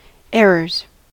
errors: Wikimedia Commons US English Pronunciations
En-us-errors.WAV